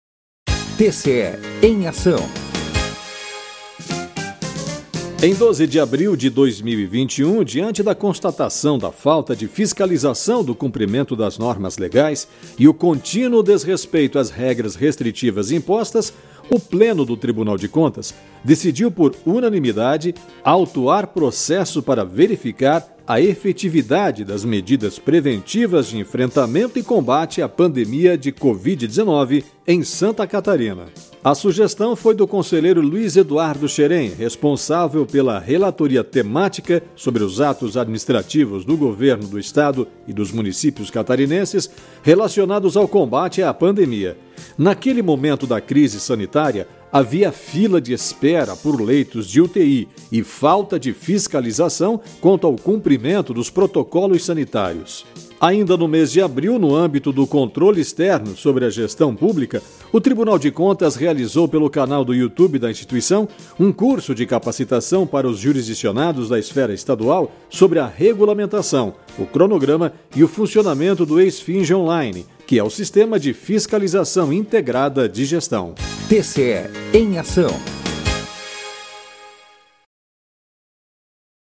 VINHETA – TCE EM AÇÃO